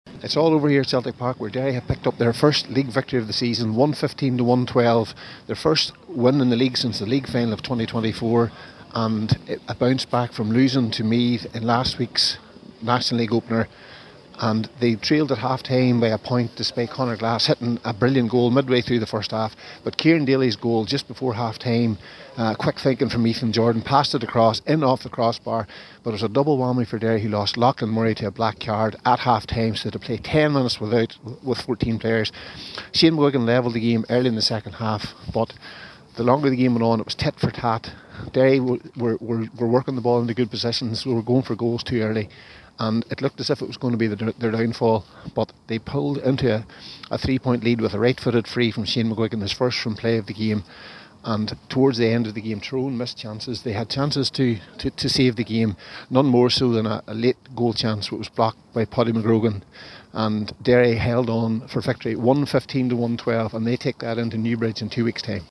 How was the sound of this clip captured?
full time report from Celtic Park…